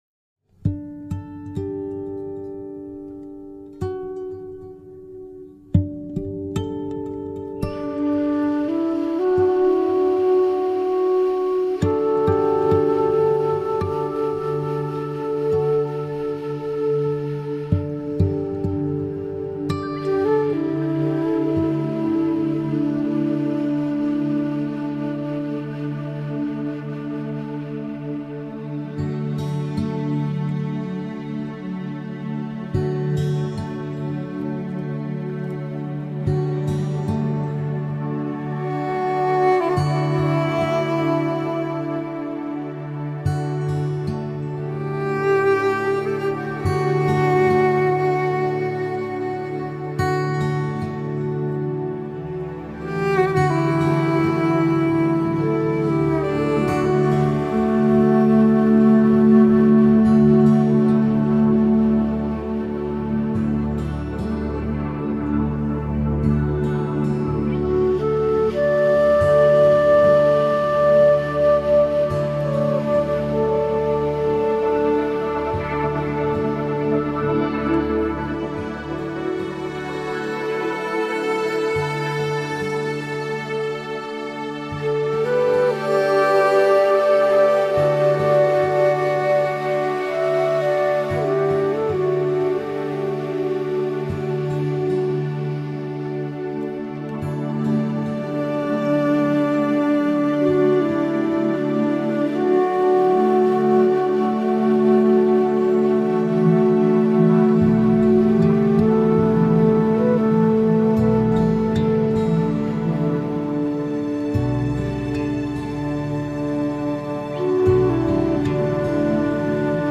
Find a quiet area and either sitting on a meditation cushion (or a cushion or rolled up blanket) or laying down comfortably, play this soothing five minute meditation track.
5-Minute-Meditation-Music-with-Earth-Resonance-Frequency-for-Deeper-Relaxation.mp3